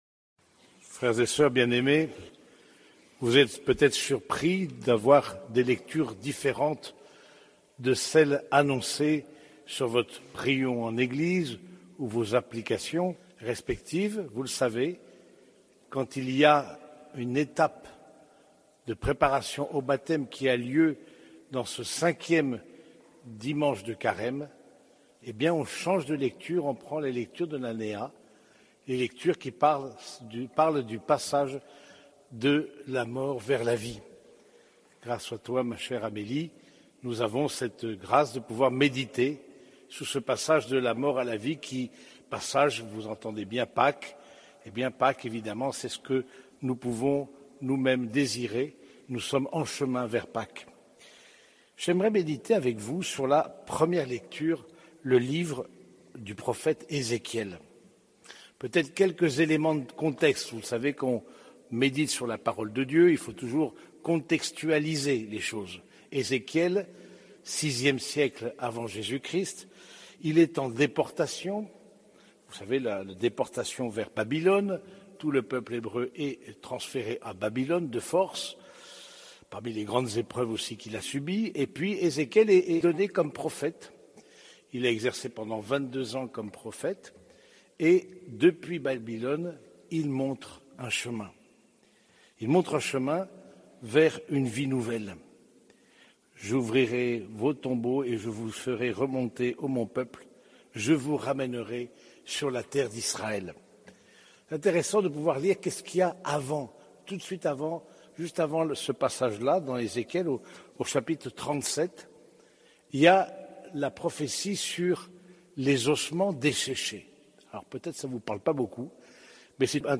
Homélie du cinquième dimanche de Carême